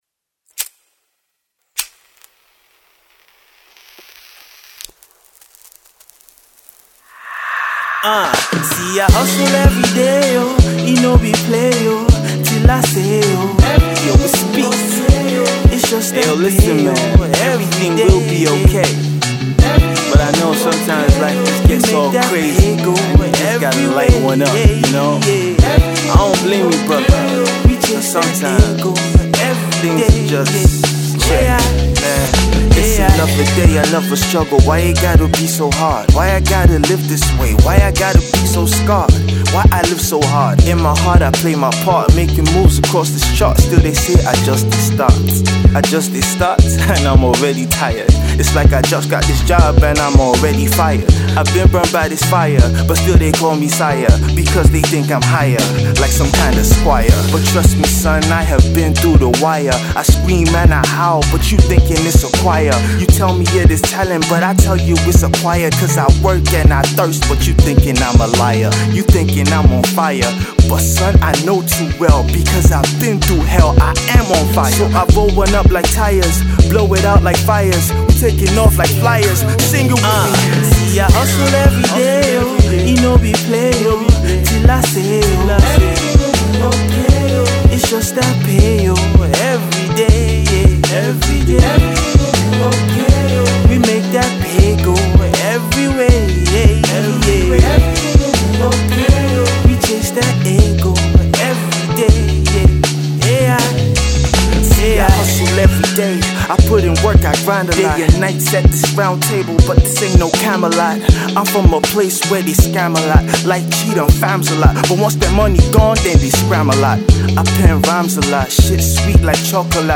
It’s Hip-Hop with a reggae touch!